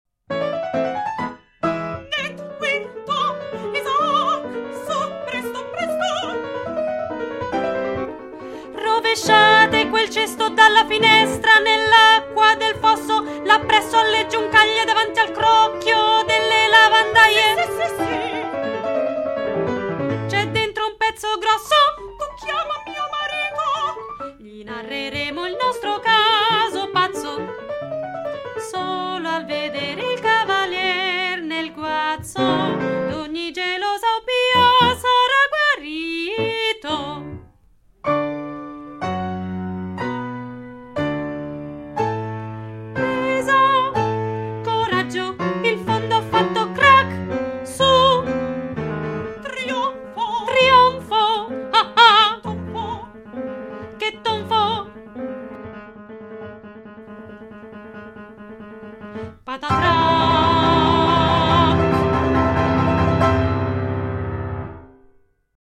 Scena della cesta – Ritmica